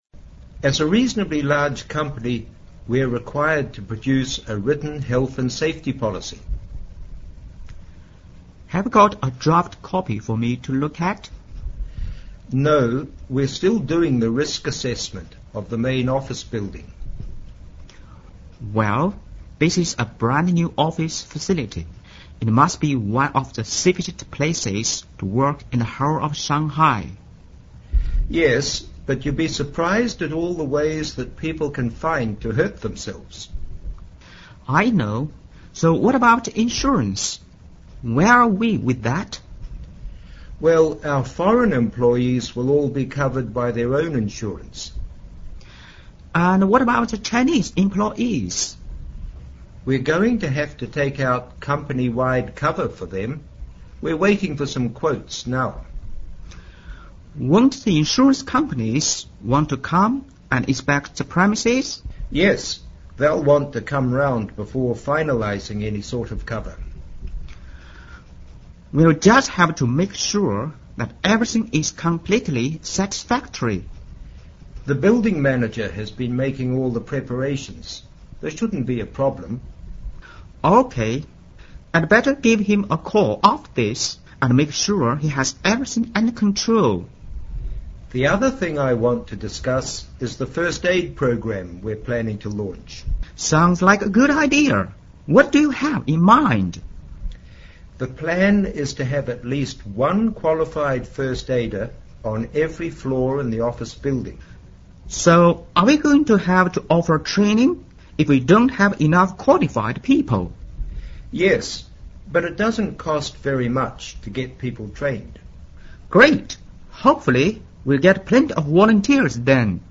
Listen to the dialogue between Human Resources Manager(HR) and Company President (CP)